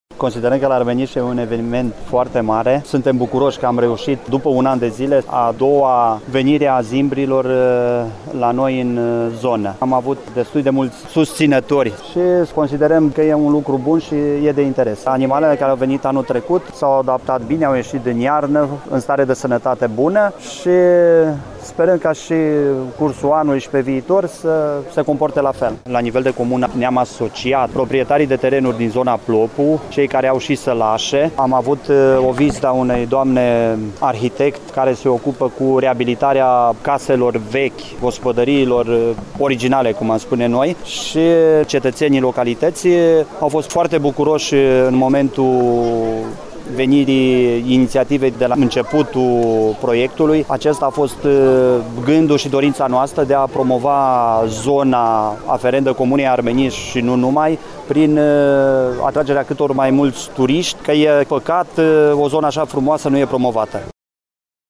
Despre acţiunea de resălbăticire şi metodele acesteia de atragere a turiştilor în zona cărăşană, Petru Vela, primarul comunei Armeniş, a precizat: